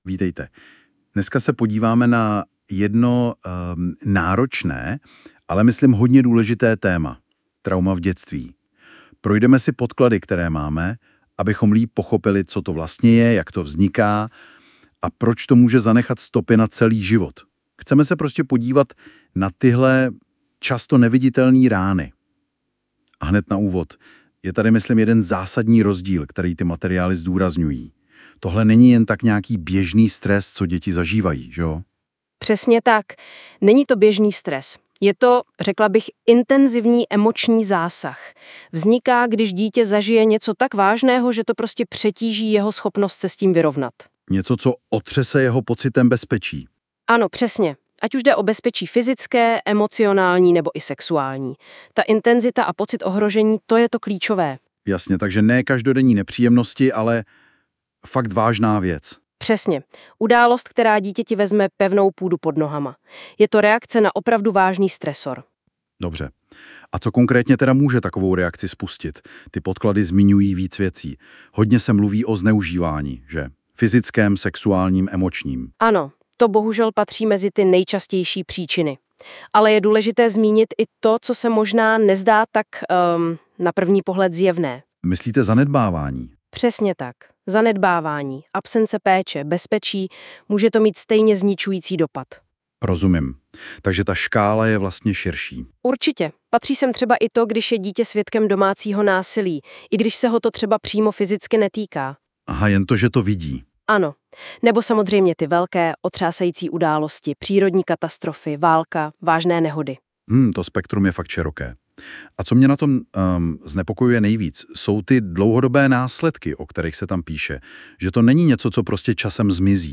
Audioverze vytvořená na základě tohoto článku pomocí nástroje NotebookLM.